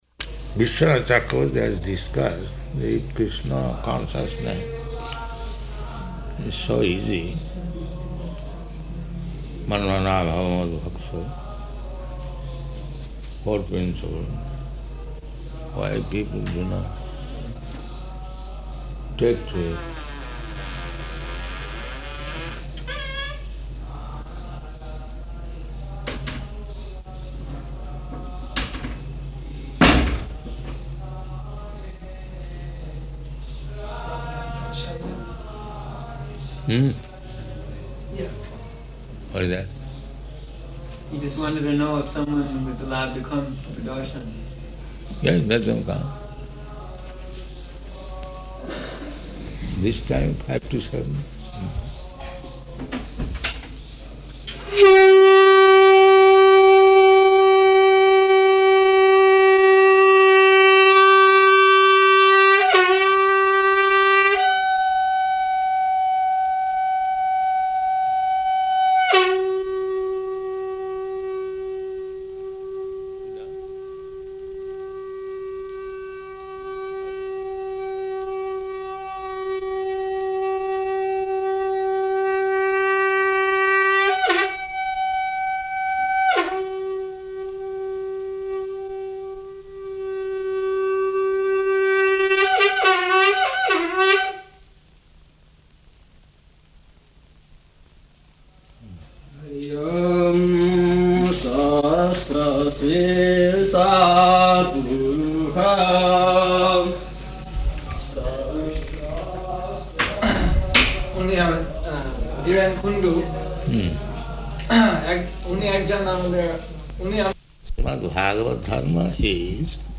Room Conversation
Room Conversation --:-- --:-- Type: Conversation Dated: January 18th 1976 Location: Māyāpur Audio file: 760118R1.MAY.mp3 Prabhupāda: ...Viśvanātha Cakravartī has discussed.